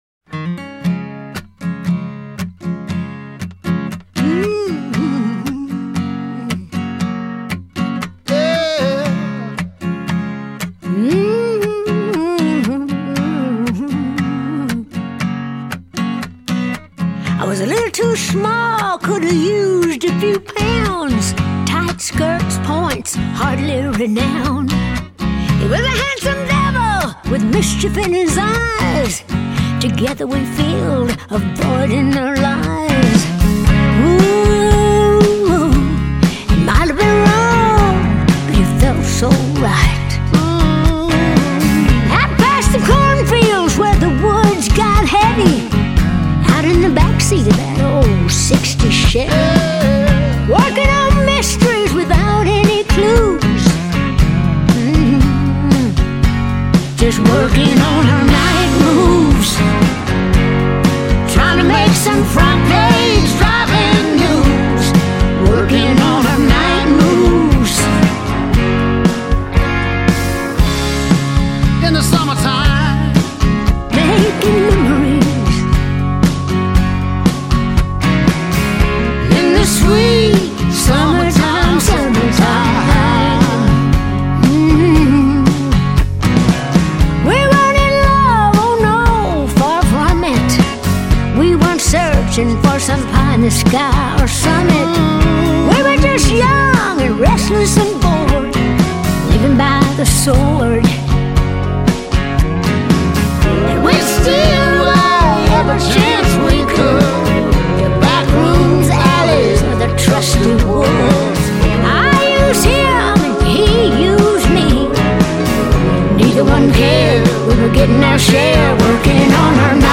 Жанр: allrock